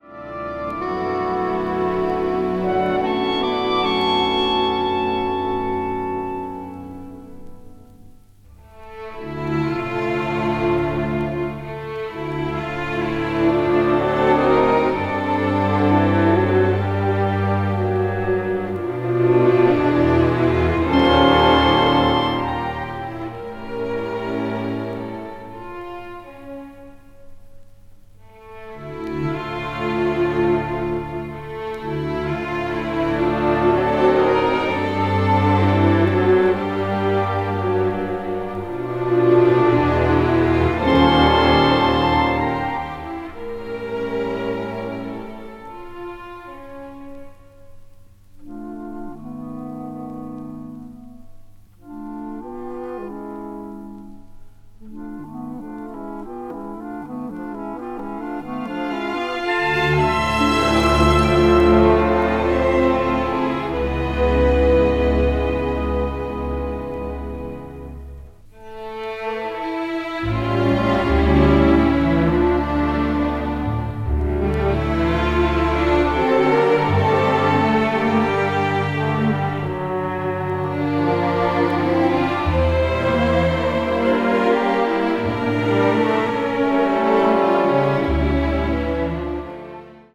media : EX/EX(some slightly noises.)
Orchestral work